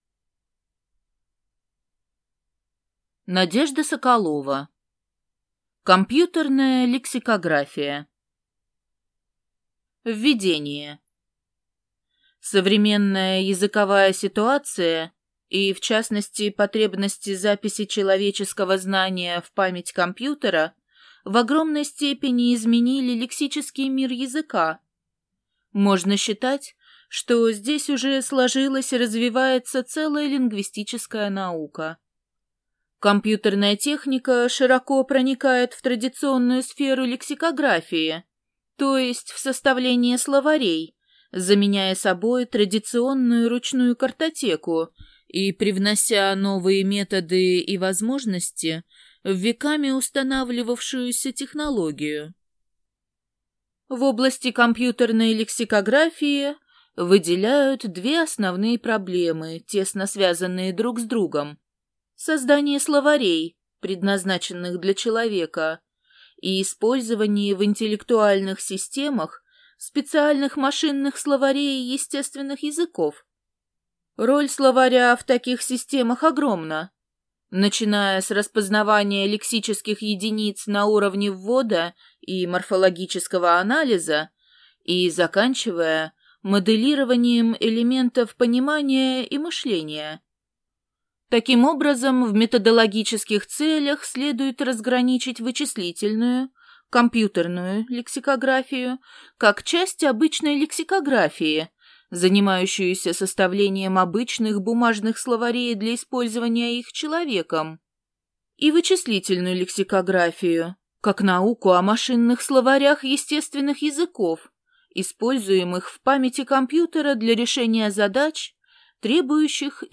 Аудиокнига Компьютерная лексикография | Библиотека аудиокниг